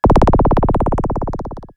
RetroGamesSoundFX